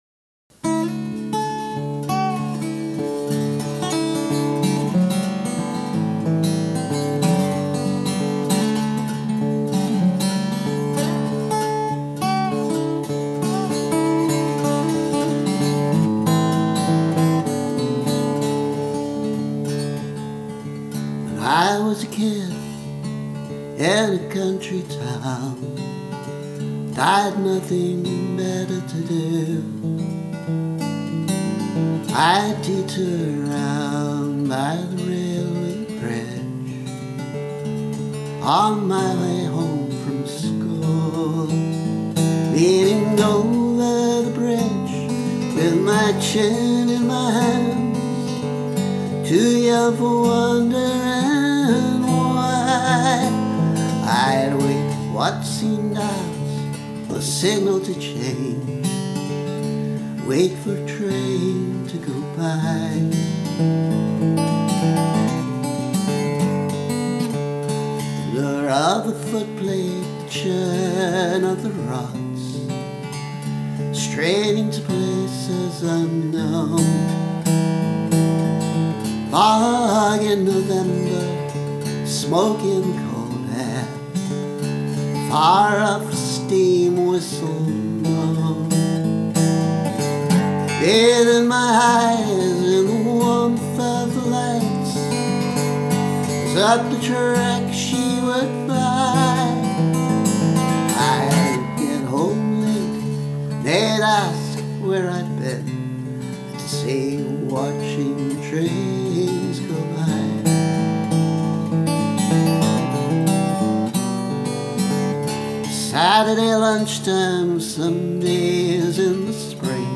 (needs removal of phantom harmony!)